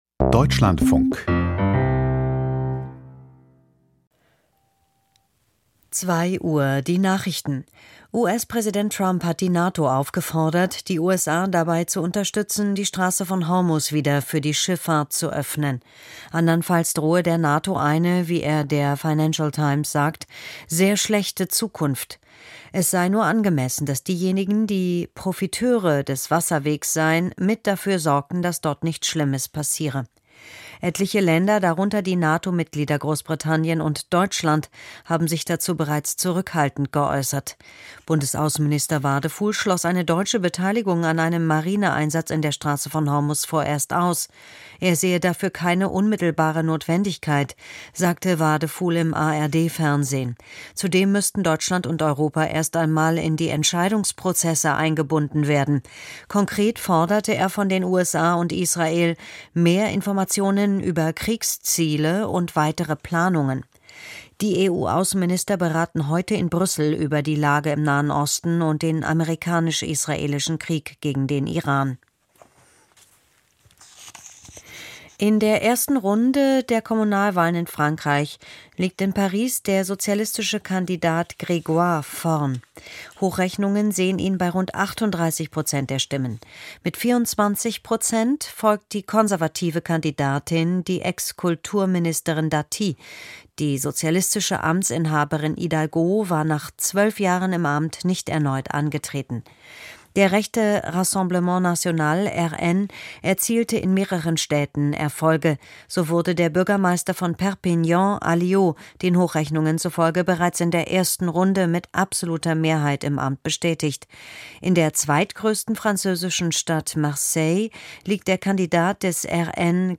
Die Nachrichten vom 16.03.2026, 01:59 Uhr
Aus der Deutschlandfunk-Nachrichtenredaktion.